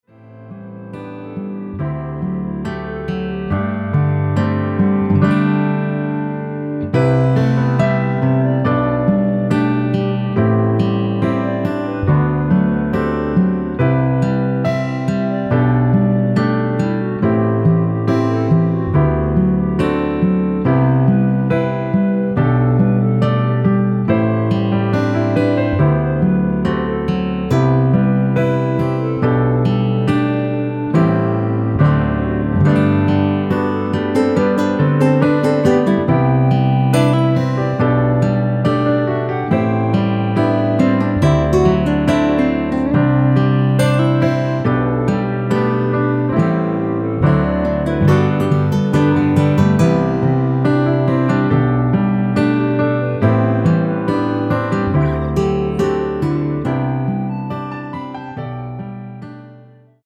원키에서(-3)내린 멜로디 포함된 1절후 후렴으로 진행되게 편곡한 MR입니다.(본문 가사 참조)
◈ 곡명 옆 (-1)은 반음 내림, (+1)은 반음 올림 입니다.
앞부분30초, 뒷부분30초씩 편집해서 올려 드리고 있습니다.
중간에 음이 끈어지고 다시 나오는 이유는